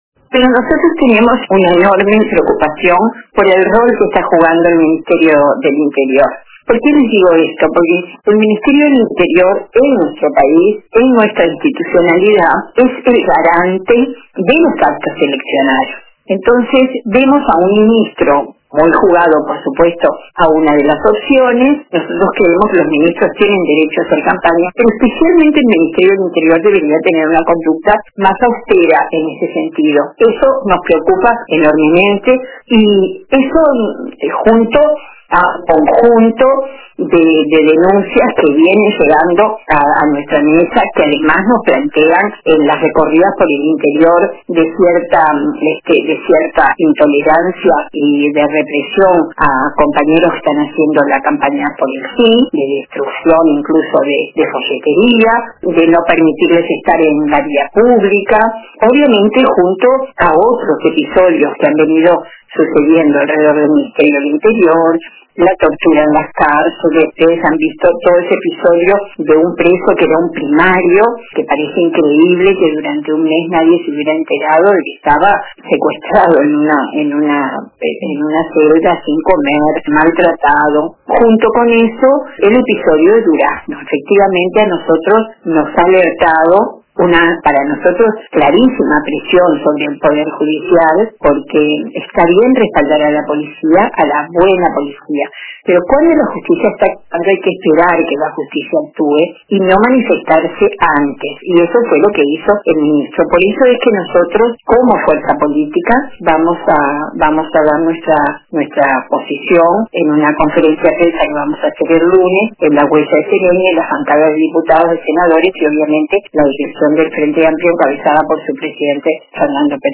La senadora frenteamplista y ex ministra de Turismo, Liliam Kechichian, participó este viernes en LA TARDE DE RBC